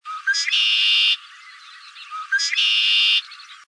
Chant du carouge à épaulettes